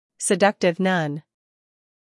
英音/ sɪˈdʌktɪv / 美音/ sɪˈdʌktɪv /